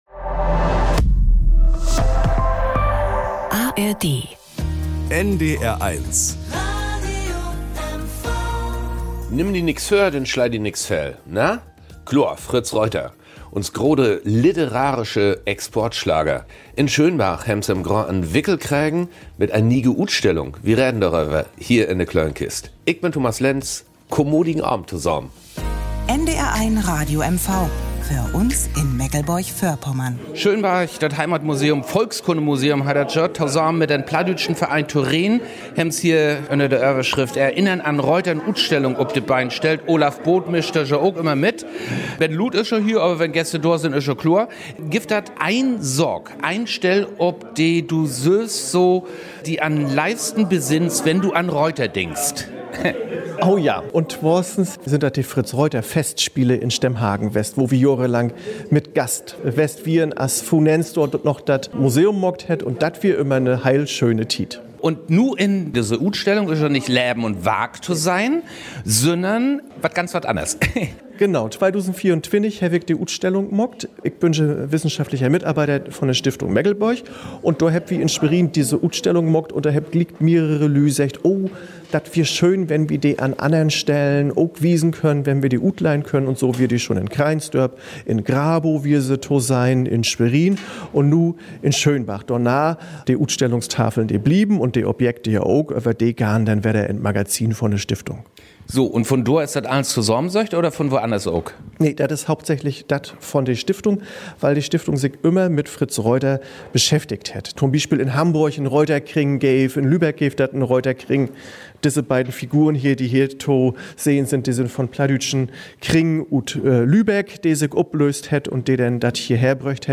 In der Sonderausstellung "Erinnern an Reuter" im Volkskundemuseum Schönberg wird schnell klar, der berühmte Mecklenburger hat seine Spuren nicht nur auf Straßenschildern, Medaillen, Urkunden, Bildern sondern auch bei einer LPG und als Kunstpreis hinterlassen. Ein munterer Hörspaziergang mitten durch das Ausstellungsgetümmel, der zeigt, was Fritz Reuter so besonders macht und warum er bis heute nicht vergessen ist.